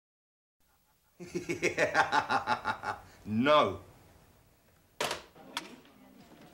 The video STARTS before the song with Ian Gillan on the phone saying “no” to being in the video.  I thought the laugh he had there reminded me of a laugh I heard him use in Black Sabbath (opening to Disturbing the Priest or the live version of the song “Black Sabbath”).  But could just be me – I’ve included just his “laugh” from the video below as an embedded mp3 file.
Ianlaugh.mp3